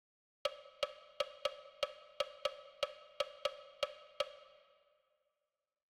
Tresillo